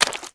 STEPS Swamp, Walk 30.wav